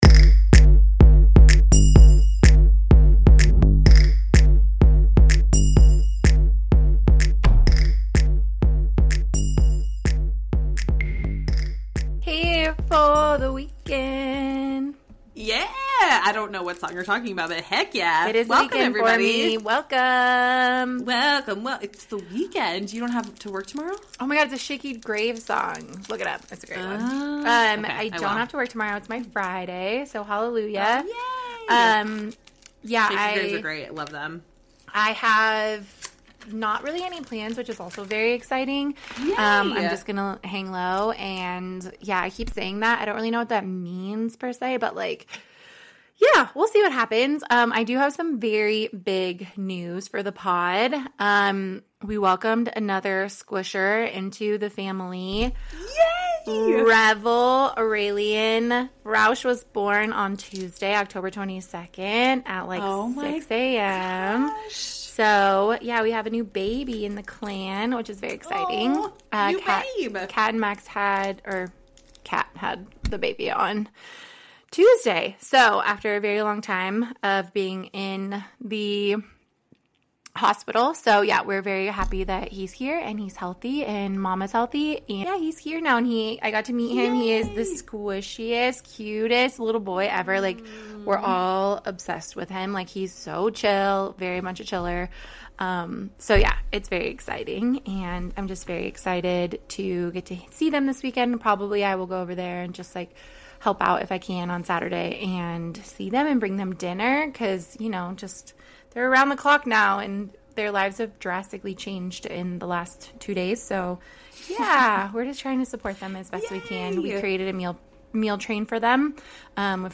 The ladies chew on mic, sorry not sorry! Taste test of all candy we didn't like and lots of random convos and sidenotes :P